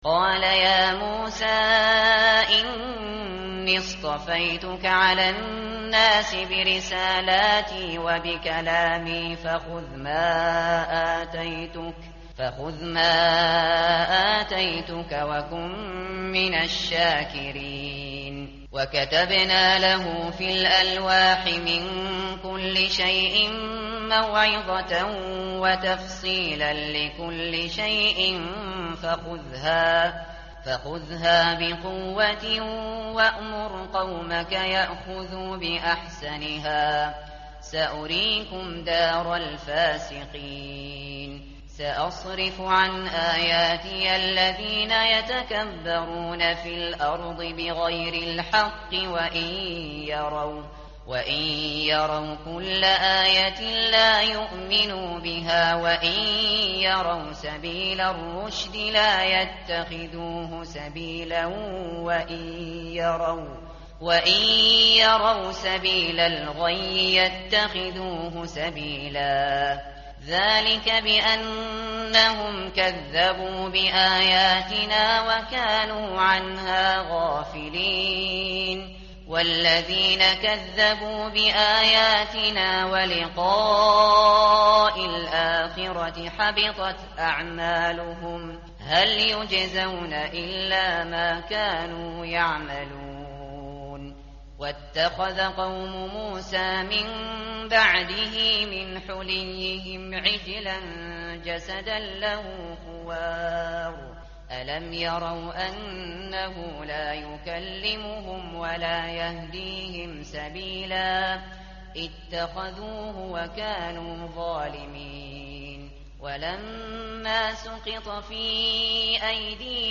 tartil_shateri_page_168.mp3